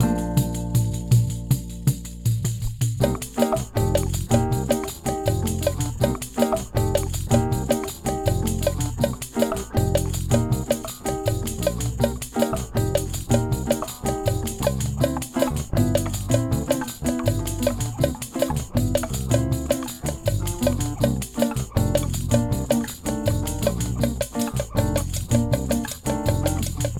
Apumateriaaliksi nauhoitin kymmenen toistoa siten, että muusikkoa säestävät harmoniset ja rytmiset instrumentit. Kaikki toistot äänitettiin kotistudiossani.